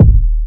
Kicks
KICK.128.NEPT.wav